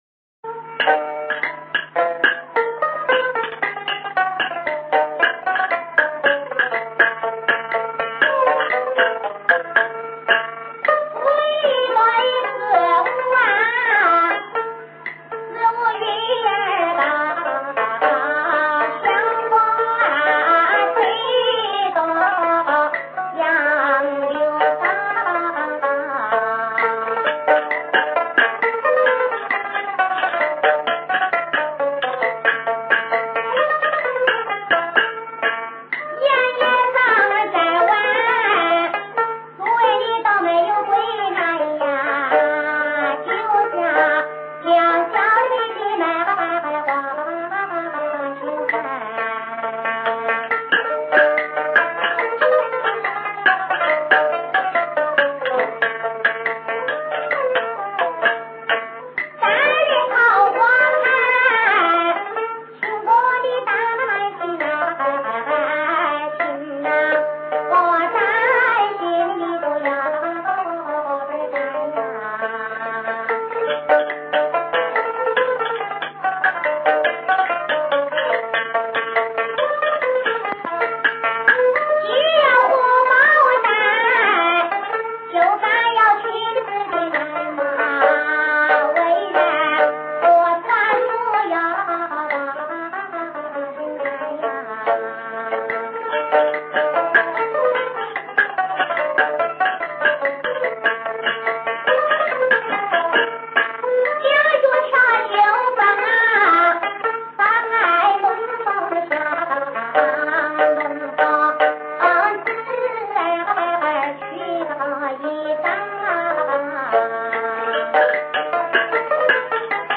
蘊生自民間土壤的說唱藝人
十八段原味酣暢的曲藝聲腔